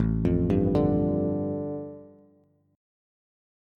Listen to A7sus4#5 strummed